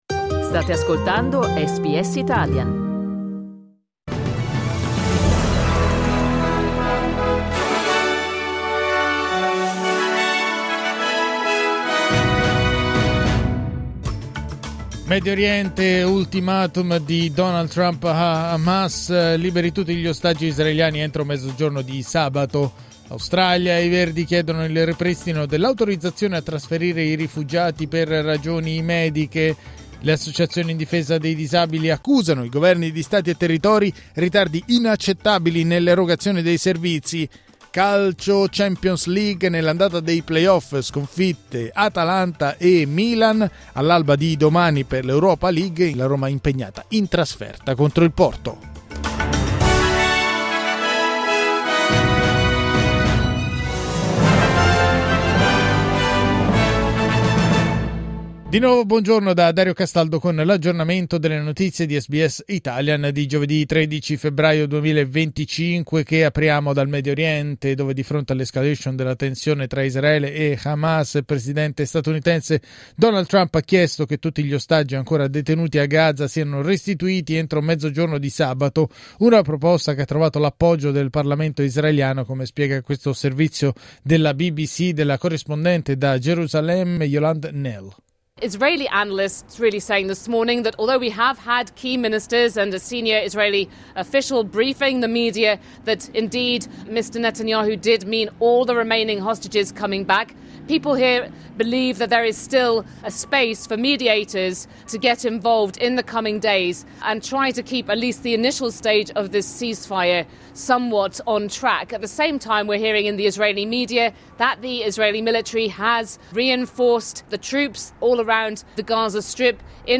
News flash giovedì 13 febbraio 2025